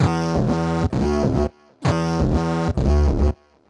Index of /musicradar/uk-garage-samples/130bpm Lines n Loops/Synths